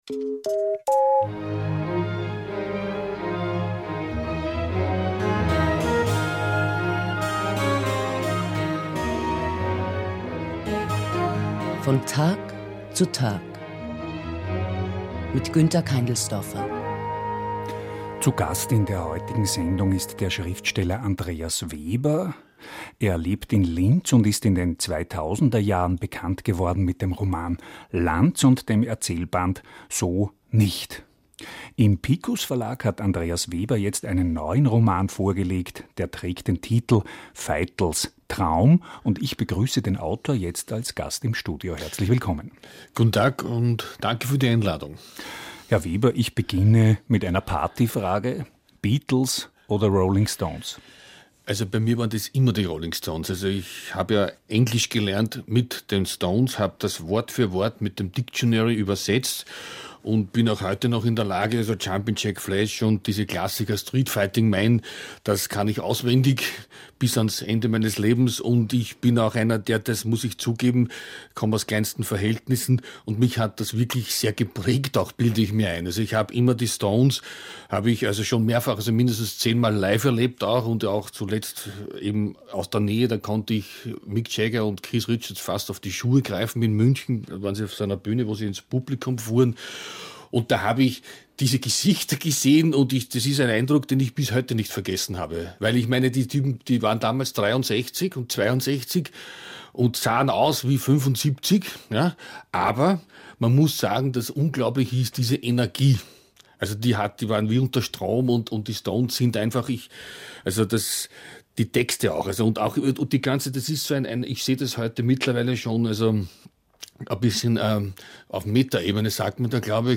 veitel_traum_oe1_interview.mp3